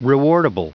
Prononciation du mot rewardable en anglais (fichier audio)
Prononciation du mot : rewardable